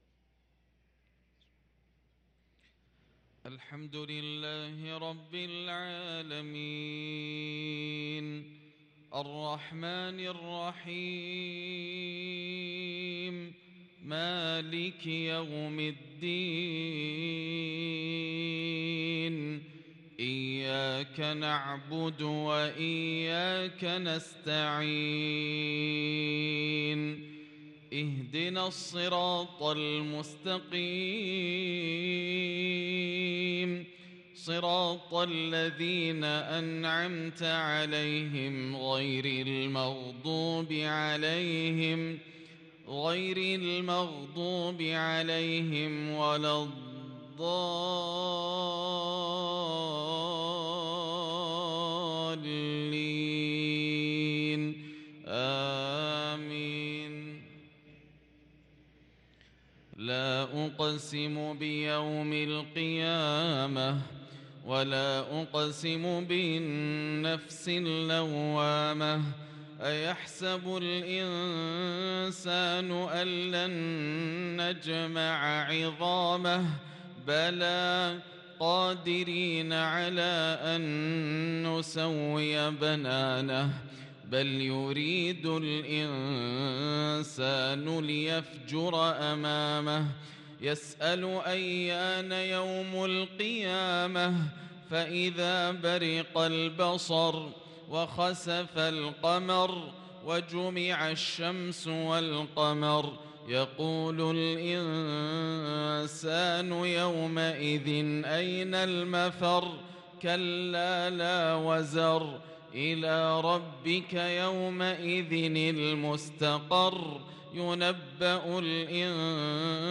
صلاة العشاء للقارئ ياسر الدوسري 21 صفر 1444 هـ